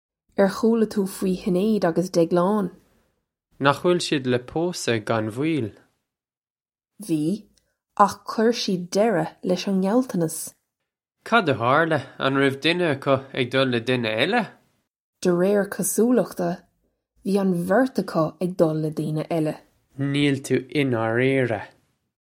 This comes straight from our Bitesize Irish online course of Bitesize lessons.